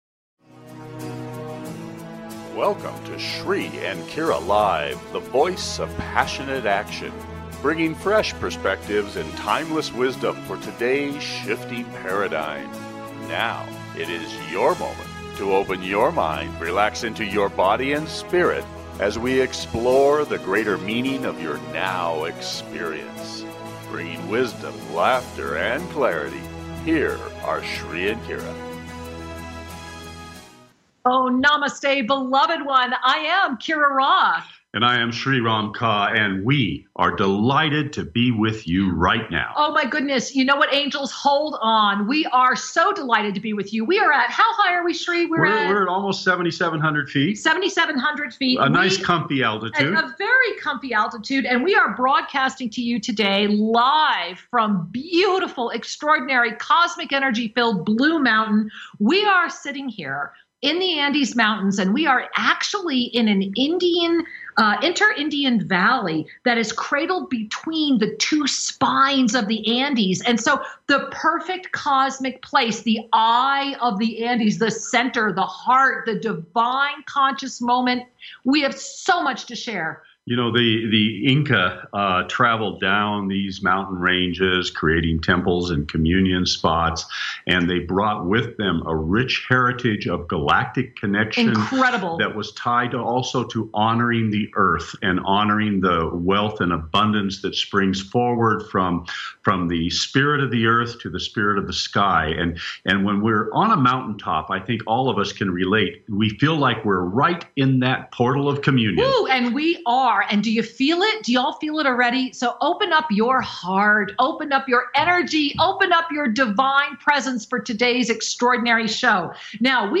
Talk Show Episode, Audio Podcast
Each week they give spiritual guidance and information on a specific topic at hand and open the phone lines to take your calls and offer mini-soul readings.